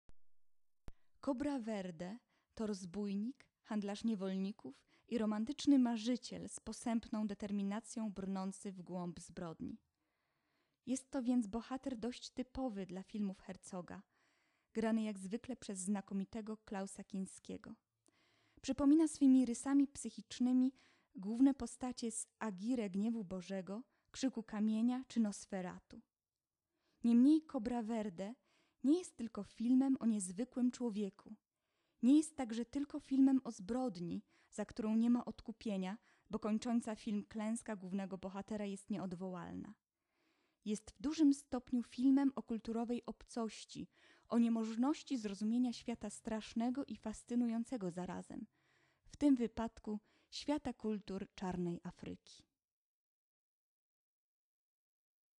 włącz  lektor